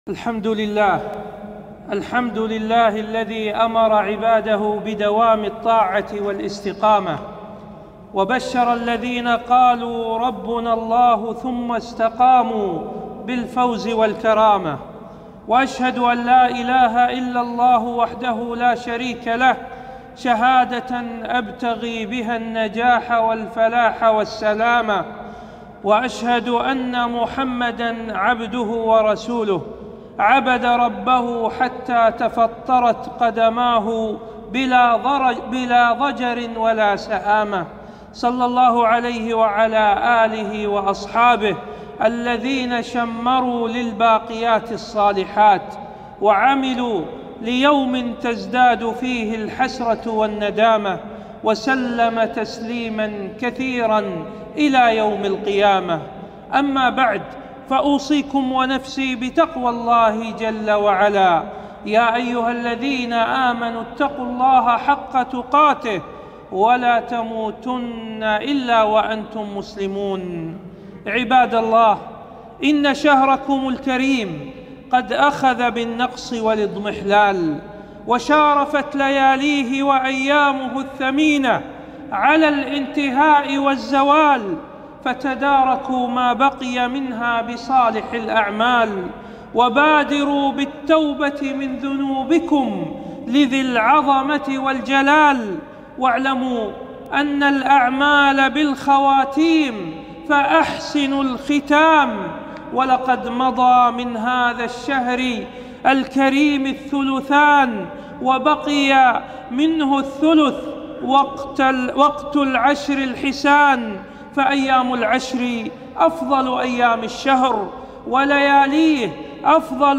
خطبة - فضل العشر الأواخر من رمضان -أهمية الزكاة